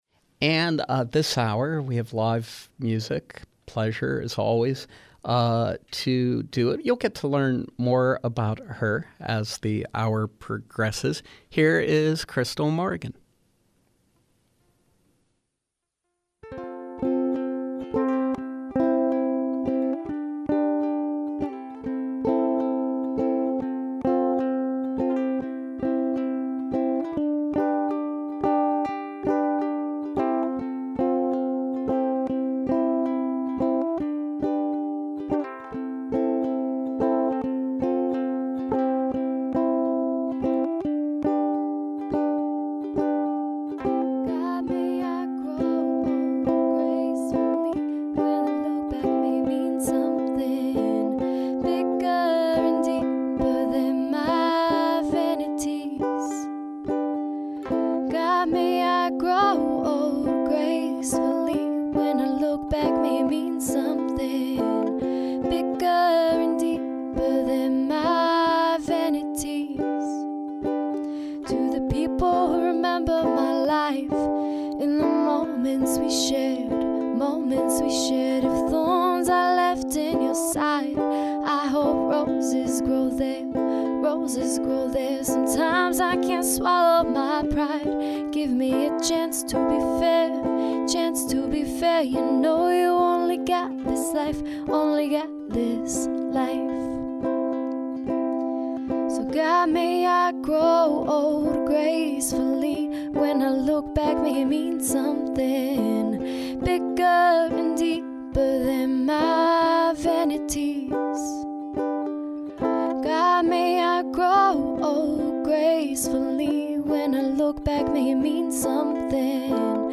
Pittsburgh-based singer/songwriter